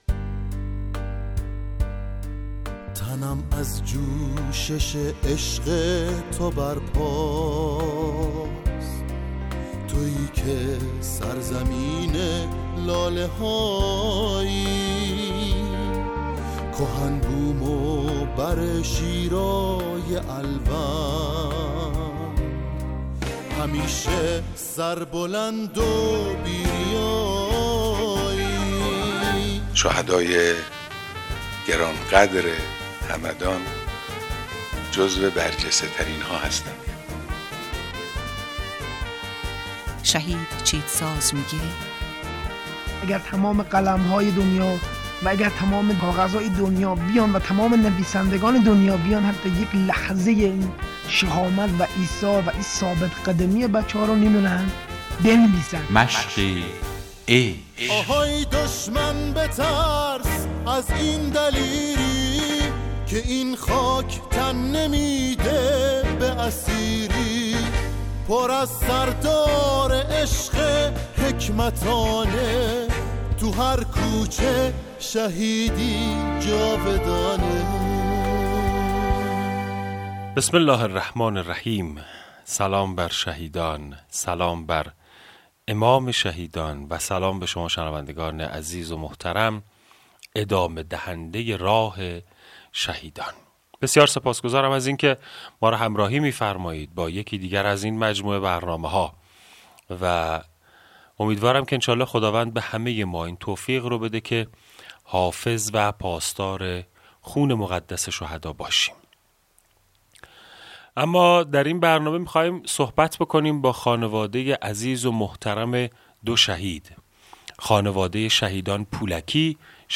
مصاحبه با خانواده شهید